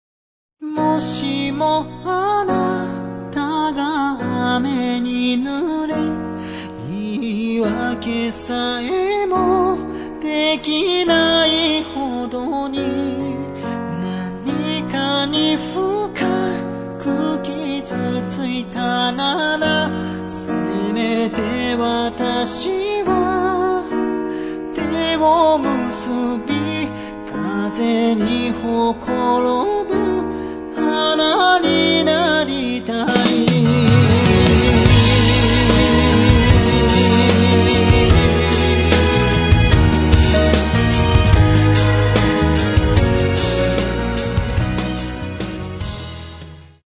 J-Pop, Japan, Lyrics
if you like Japan folk songs with a modern fusion